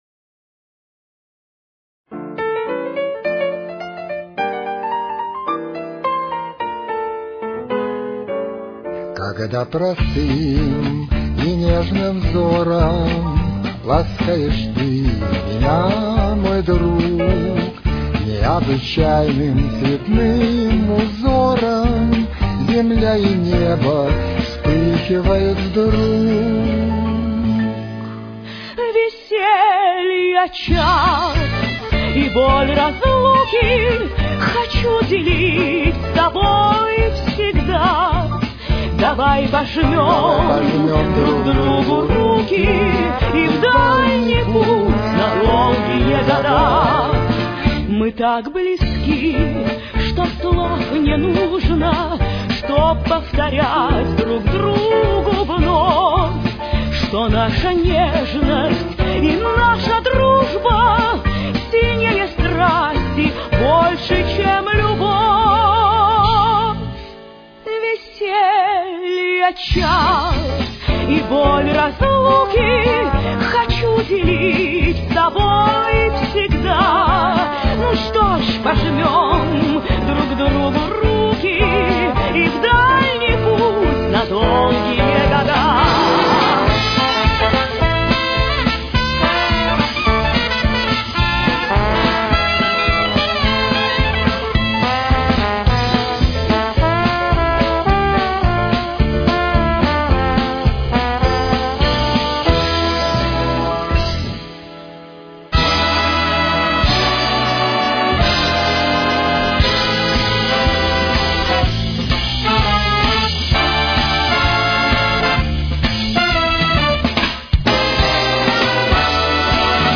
с очень низким качеством (16 – 32 кБит/с)
Ре минор. Темп: 106.